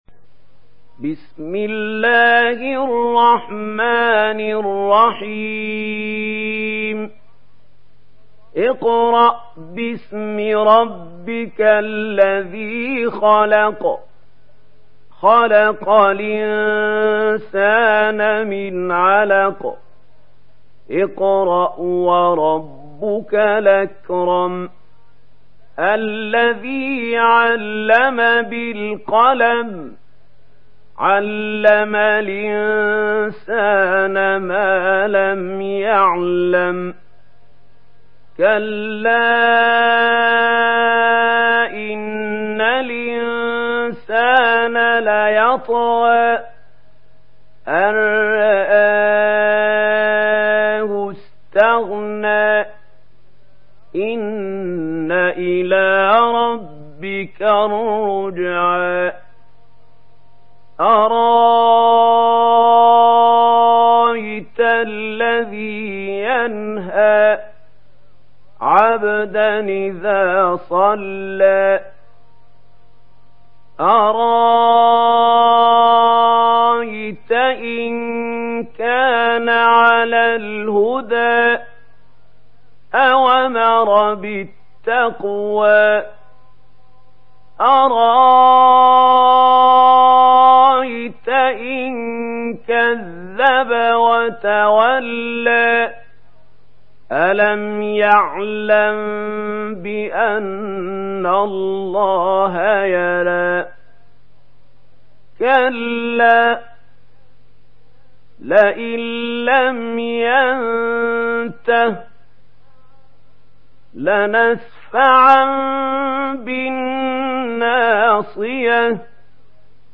Surah العلق MP3 in the Voice of محمود خليل الحصري in ورش Narration
Listen and download the full recitation in MP3 format via direct and fast links in multiple qualities to your mobile phone.
مرتل ورش عن نافع